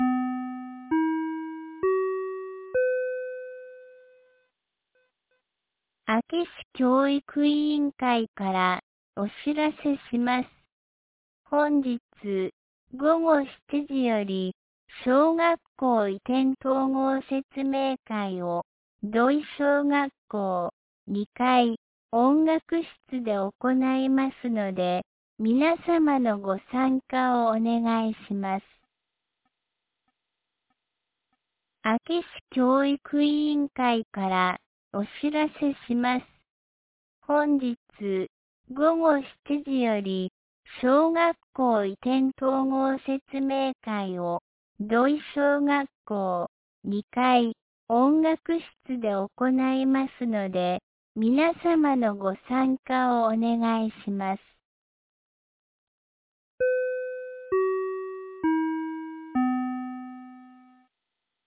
2026年01月22日 17時21分に、安芸市より土居、僧津へ放送がありました。